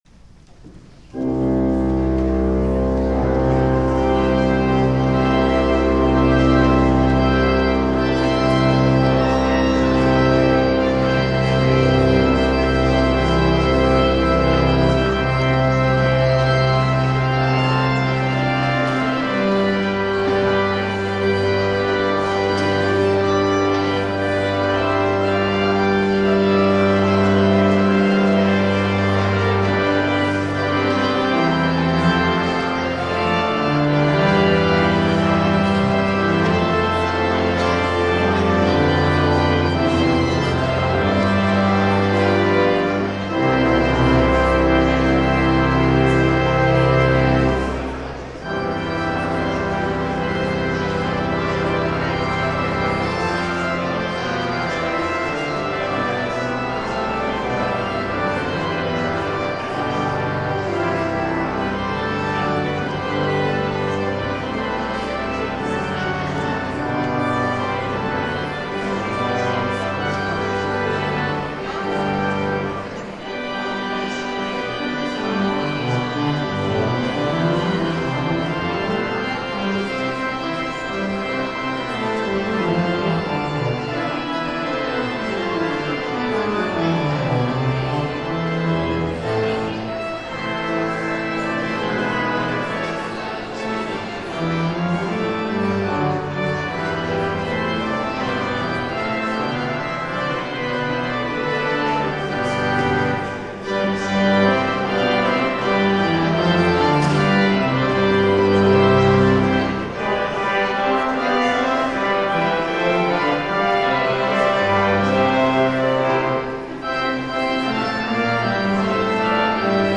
*THE POSTLUDE